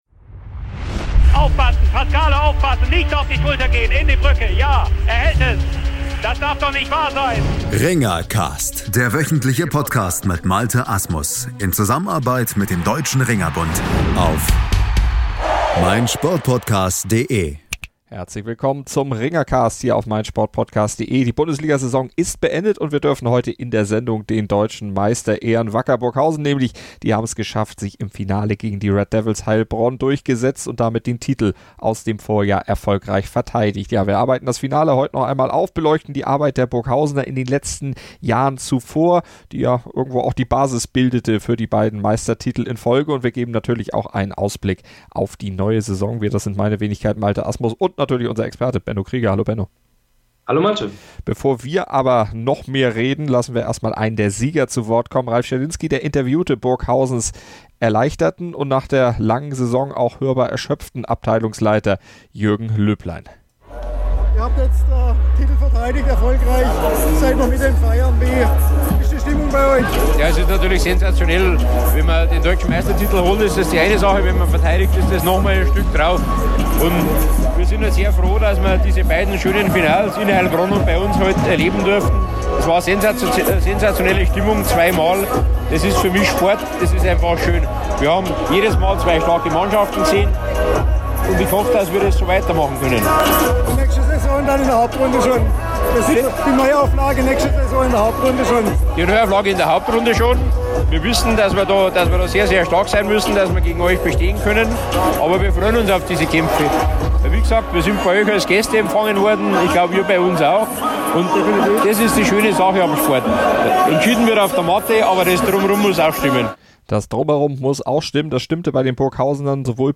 Und wir geben natürlich auch einen Ausblick auf die neue Saison und hören die Stimmen der Sieger und Besiegten.